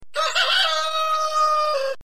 Die Farmerama Tierstimmen
Huhn